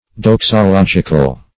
Search Result for " doxological" : The Collaborative International Dictionary of English v.0.48: Doxological \Dox`o*log"ic*al\, a. Pertaining to doxology; giving praise to God.